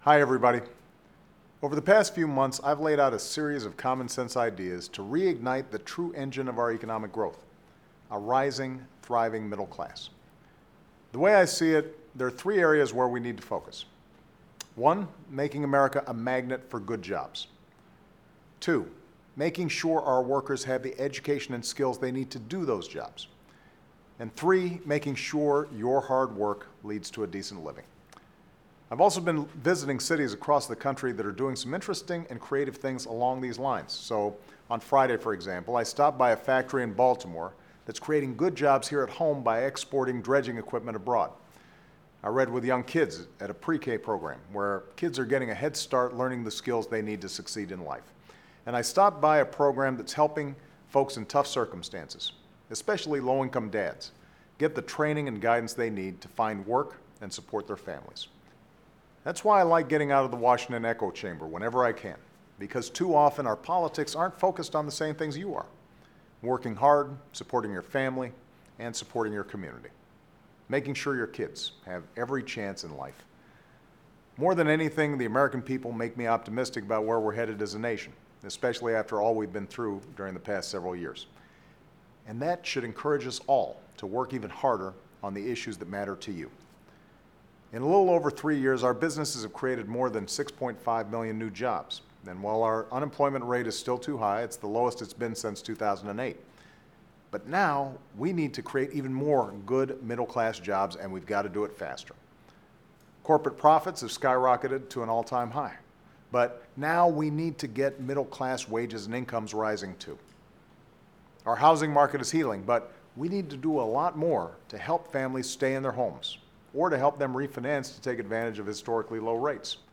Remarks of President Barack Obama
Weekly Address